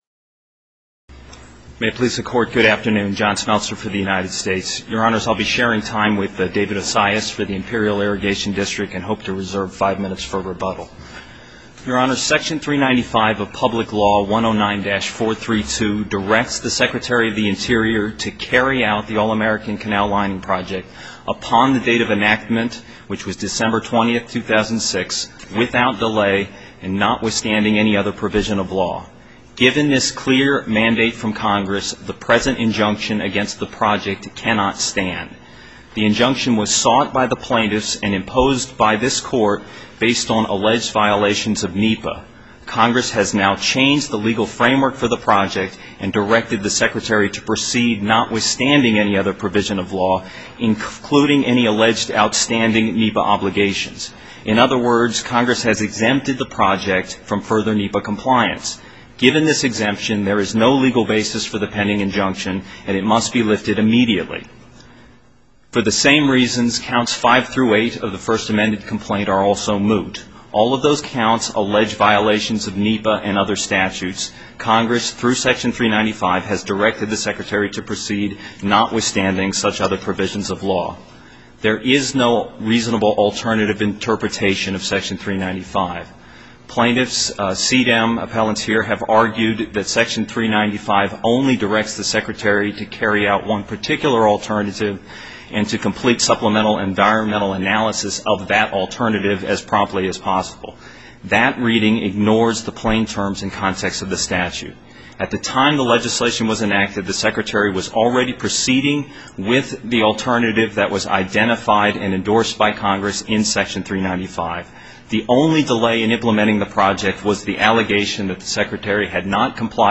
Audio-Final-Oral-Arguments.wma